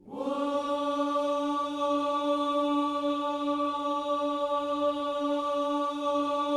WHOO D 4A.wav